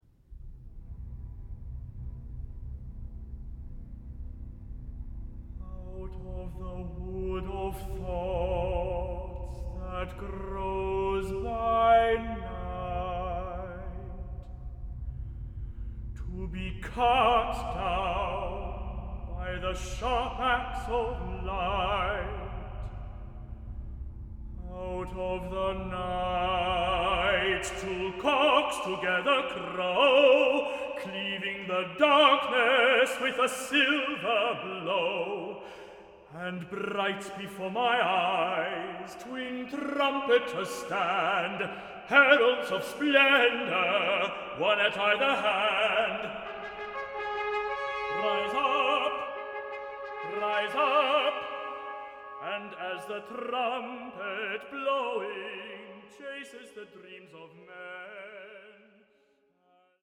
Choir & Baroque Orchestra